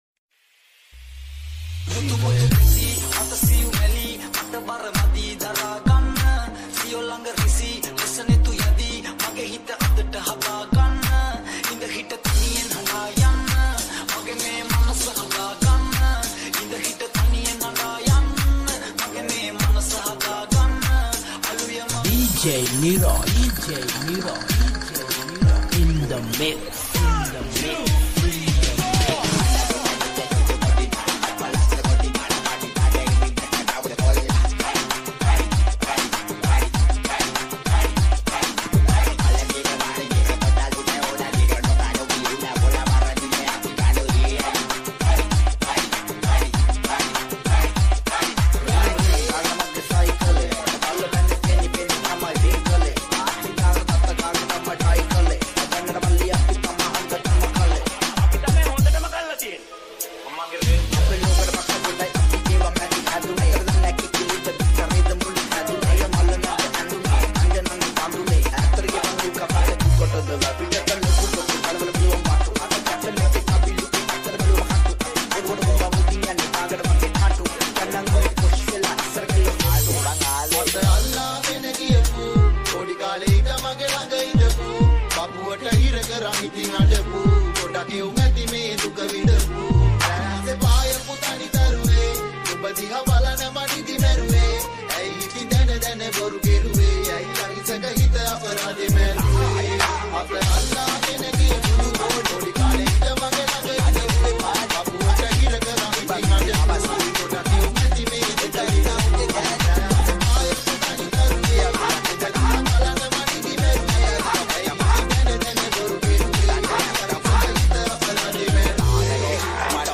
New Sinhala Dj Remix